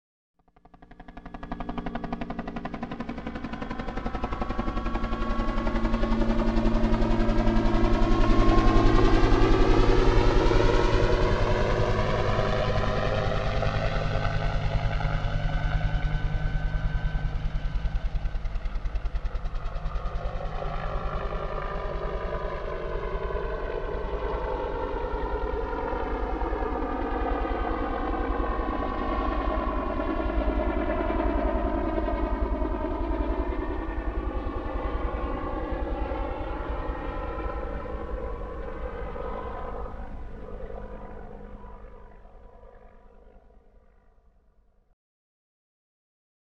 Suono di volo del Mi-24
• Categoria: Suoni di volo